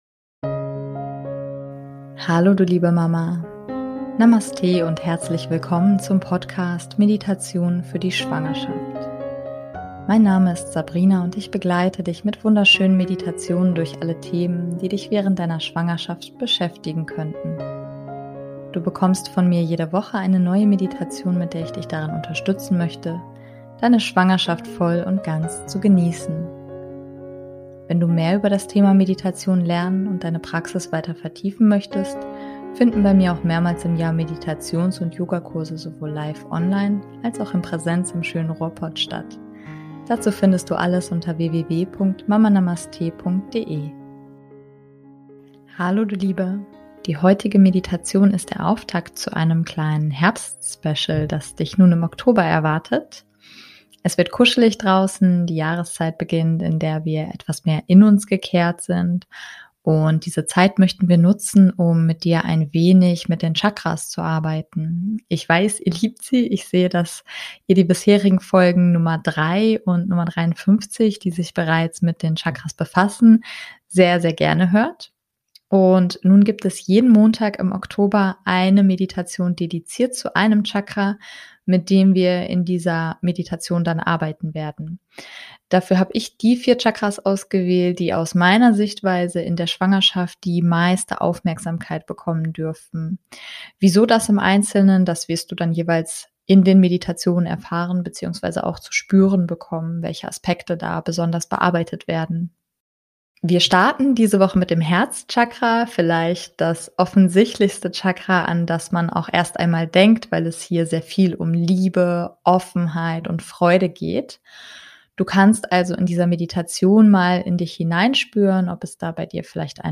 Beschreibung vor 4 Jahren Die heutige Meditation ist der Auftakt zu einem kleinen Herbstspecial, das dich nun im Oktober erwartet.